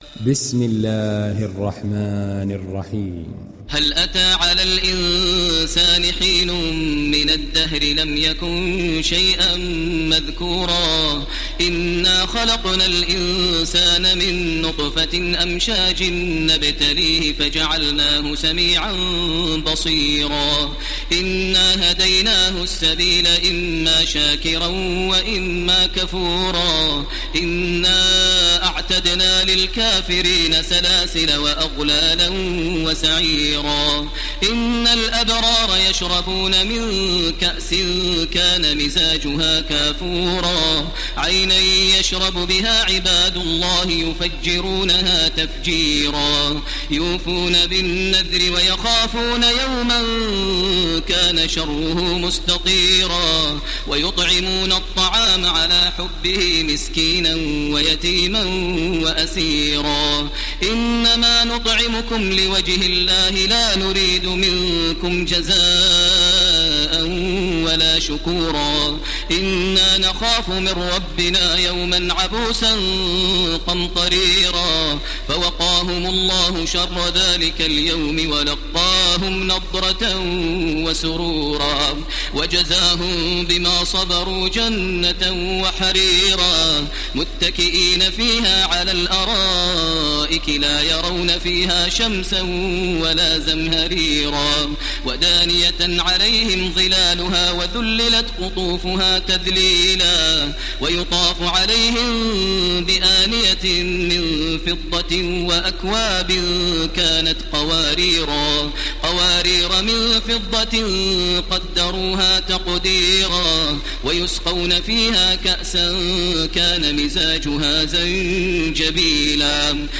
تحميل سورة الإنسان mp3 بصوت تراويح الحرم المكي 1430 برواية حفص عن عاصم, تحميل استماع القرآن الكريم على الجوال mp3 كاملا بروابط مباشرة وسريعة
تحميل سورة الإنسان تراويح الحرم المكي 1430